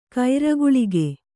♪ kaira guḷige